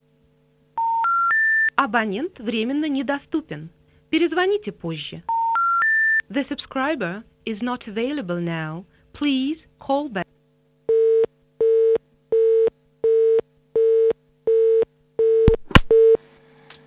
Звук пропущенного звонка вариант 3